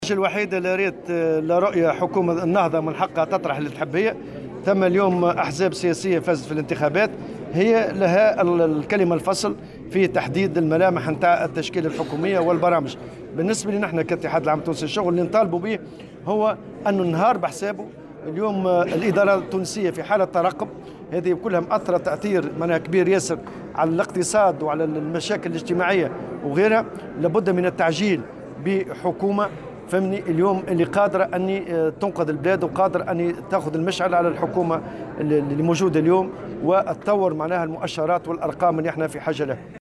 وأضاف في تصريح اليوم لمراسلة "الجوهرة أف أم" على هامش نقطة إعلامية لحركة النهضة لعرض تصورها لبرنامج عمل الحكومة القادمة، أنه لابد من الإسراع بتشكيل حكومة تكون قادرة على إنقاذ البلاد، مضيفا "النهار بحسابو".